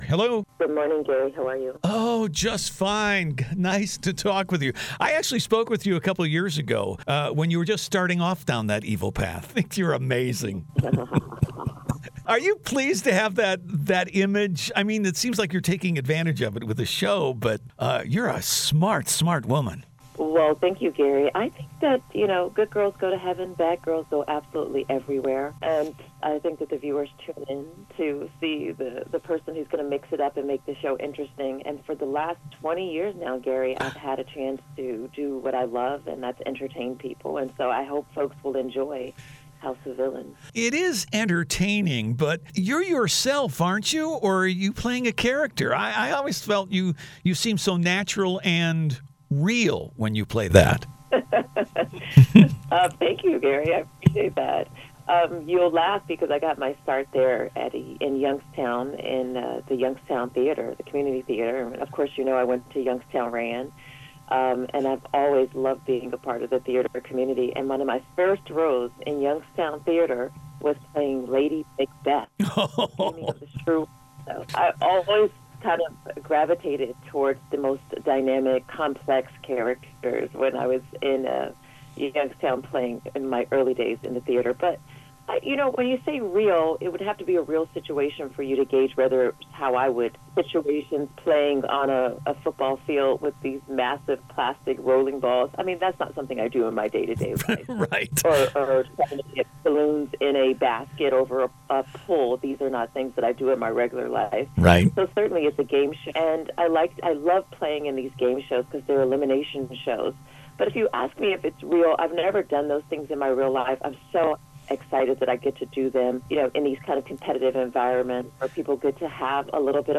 OMAROSA INTERVIEW https